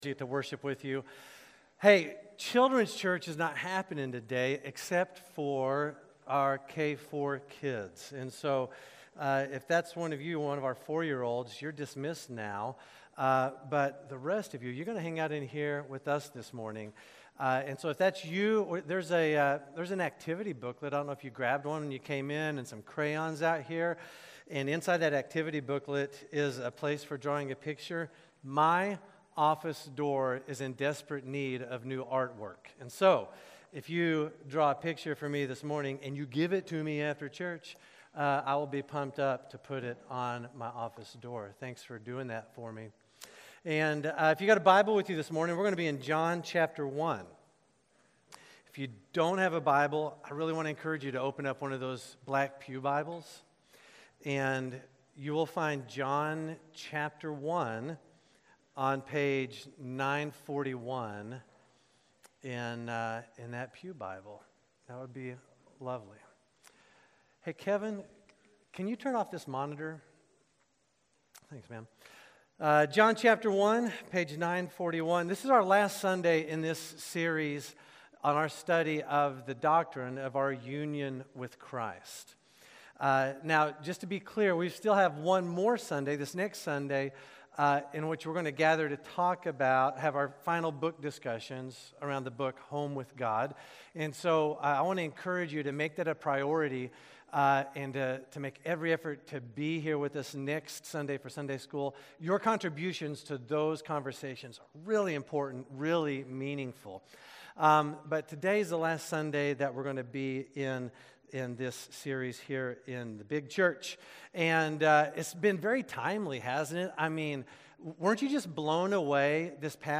South Shore Baptist Church Sermons
Sermons from South Shore Baptist Church: Hingham, MA